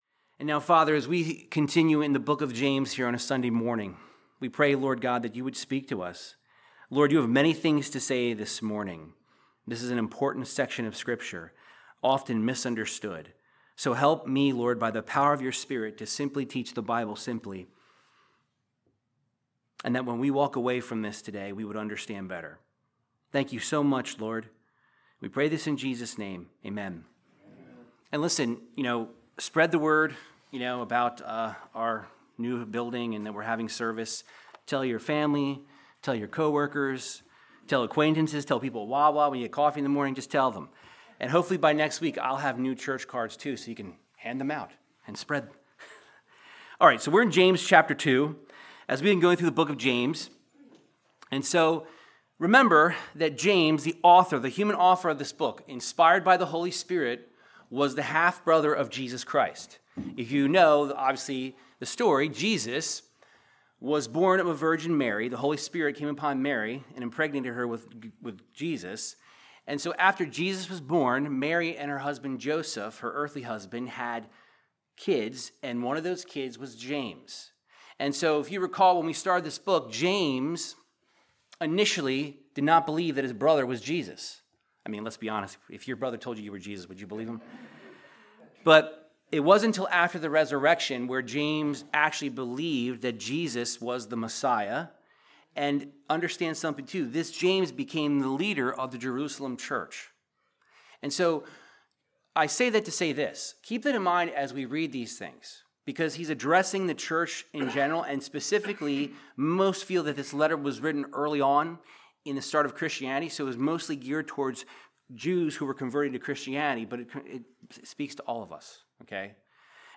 Verse By Verse Bible Teaching from James 2:14-26 discussing faith and the actions that prove your faith.